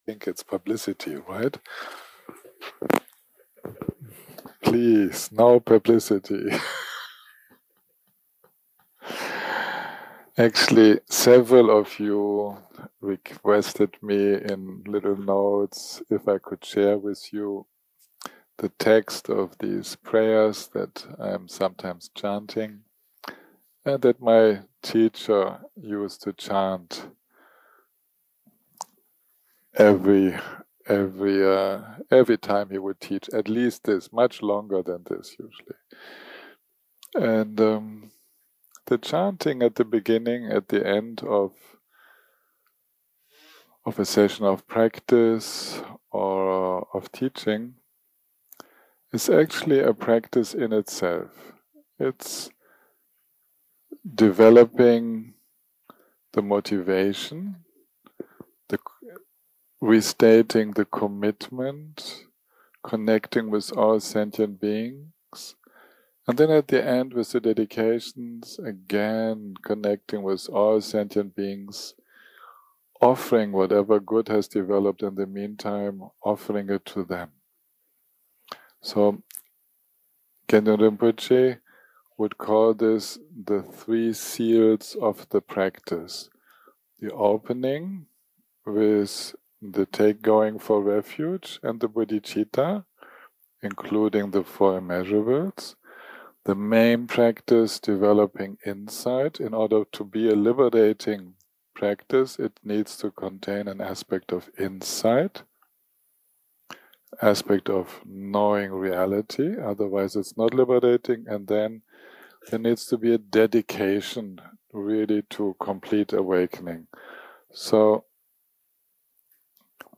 day 4 - recording 13 - Morning part1 - Guided Chanting Meditation + Discussion - Three Aspects of Experience
Dharma type: Guided meditation